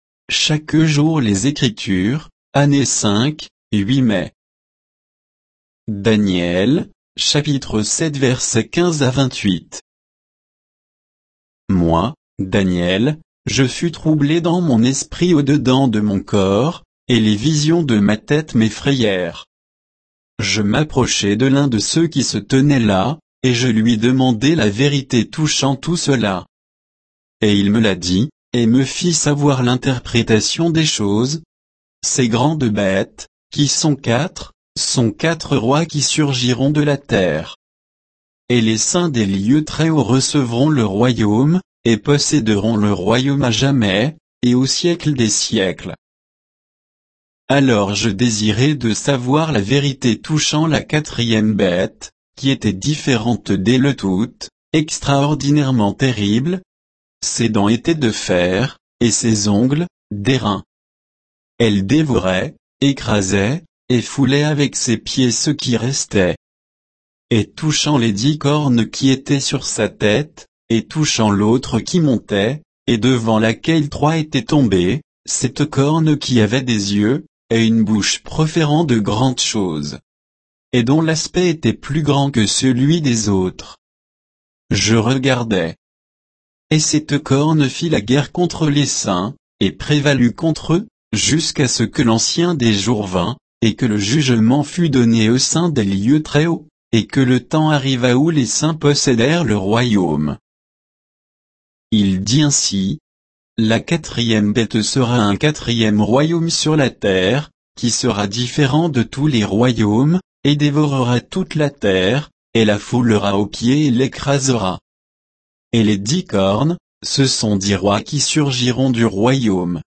Méditation quoditienne de Chaque jour les Écritures sur Daniel 7